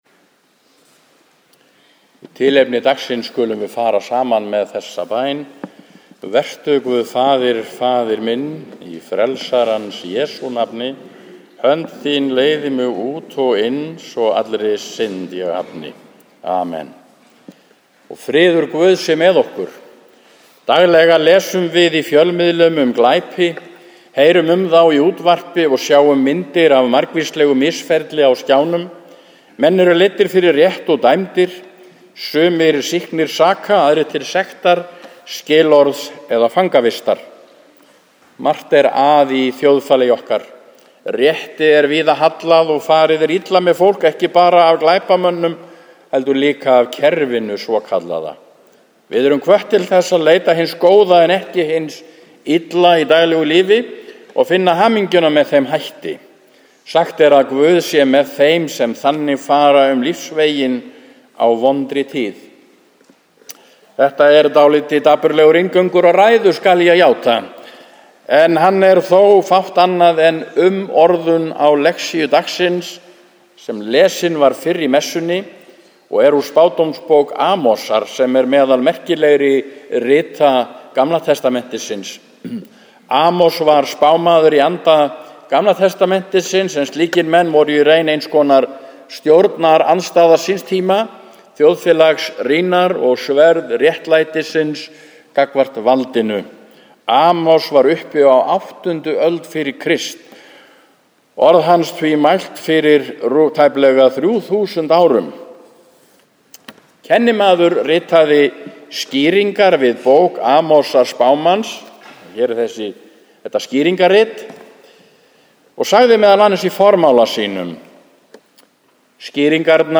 „Af heitum dreyra“ – prédikun við messu í Neskirkju 28. okt. 2013